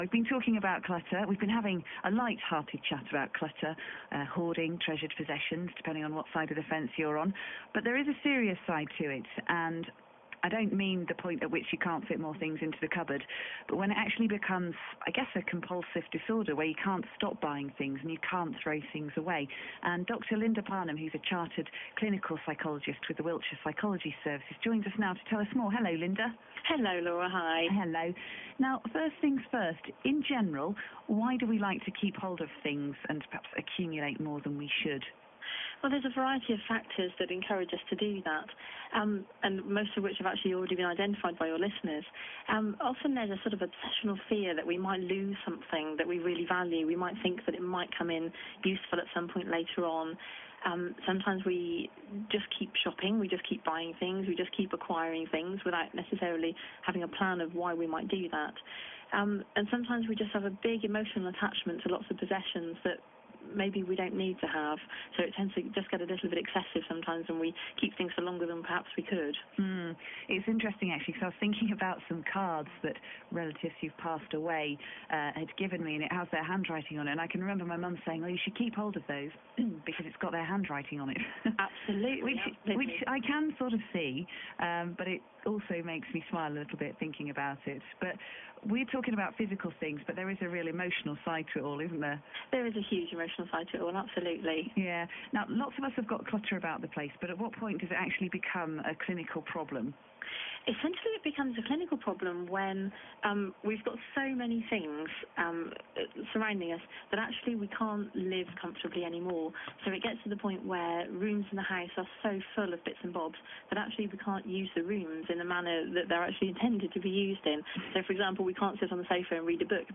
BBC Radio Interview.mp3